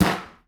Hit3.aif